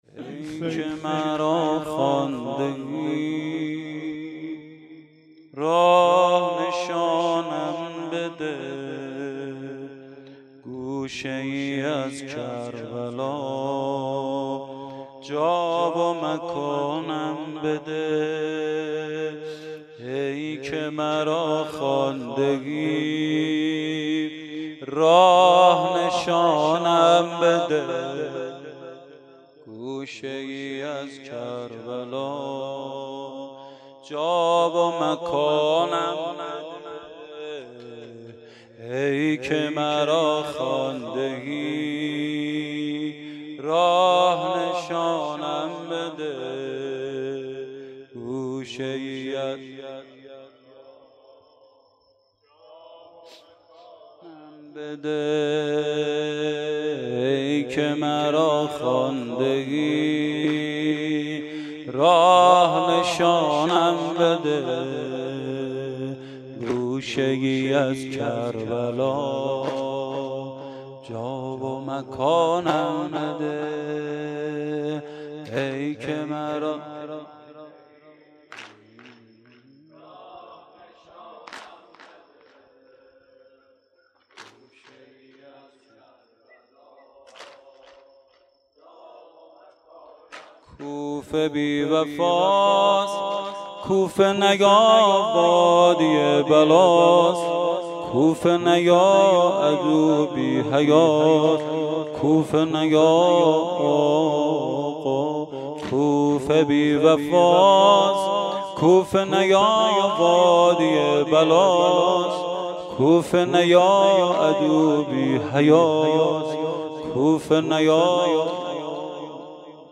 شب اول محرم 1440